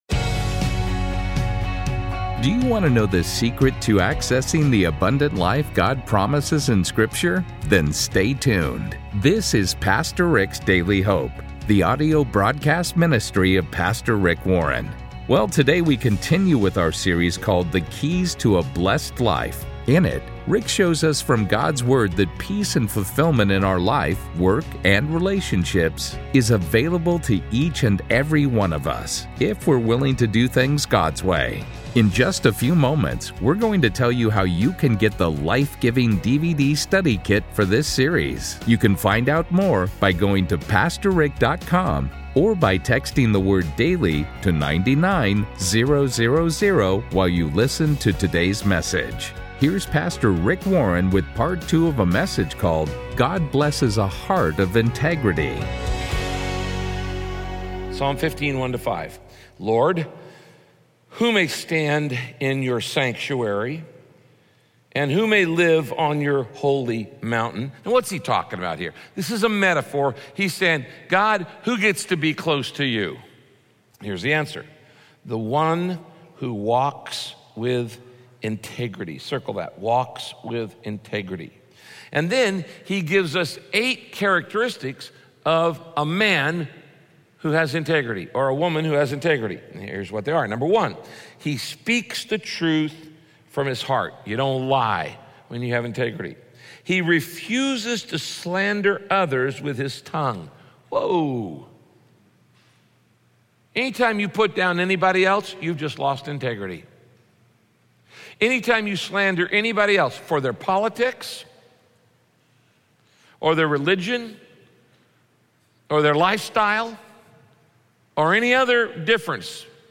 Radio Broadcast God Blesses a Heart of Integrity – Part 2 If you are serious about becoming a man or woman of integrity, the first step is to admit that you haven’t had integrity.